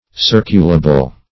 Circulable \Cir"cu*la*ble\, a. That may be circulated.